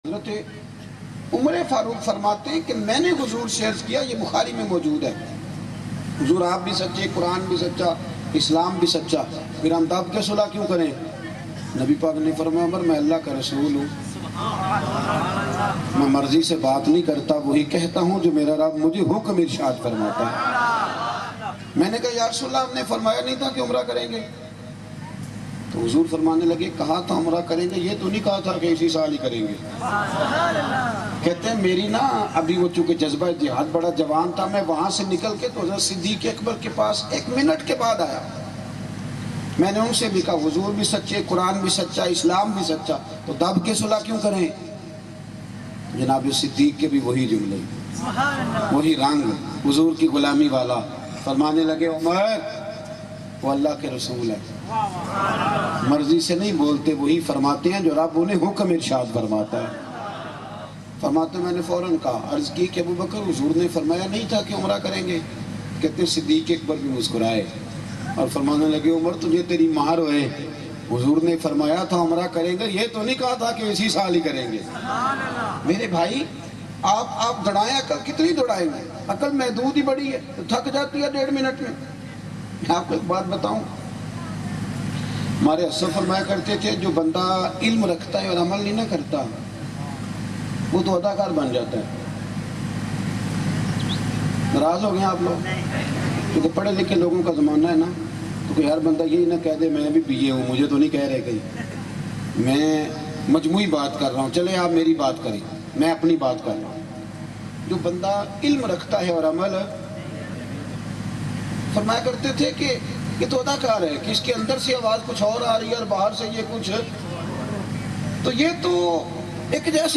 Jo ilam Rakhta Hy Par Amal Nahi Rakhta Bayan